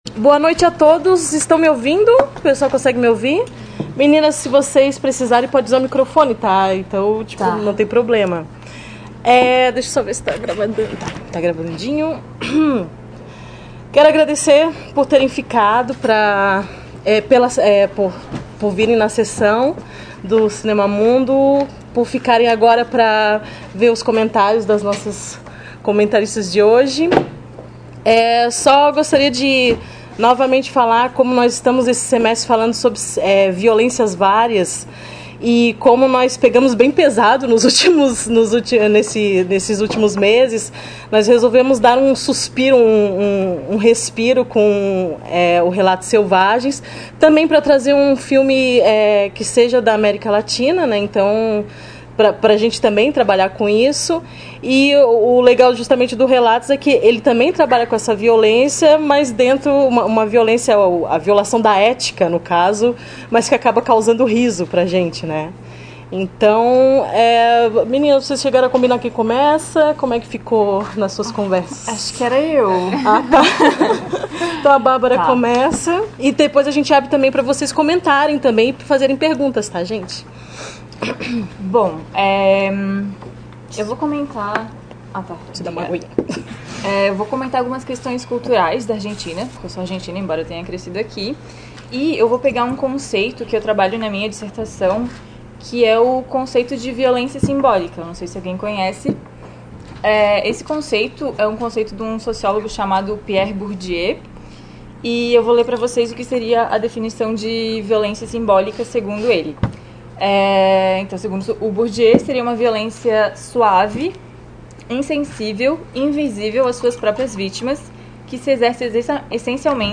Nesta seção você encontrará os áudios dos comentários realizados sobre cada um dos filmes apresentados nas edições do Projeto Cinema Mundo.